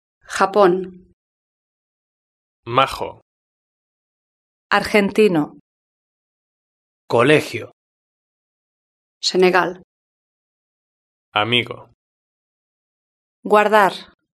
Ahora escuche cómo se pronuncian las siguientes palabras.
Los sonidos /X/ y /g/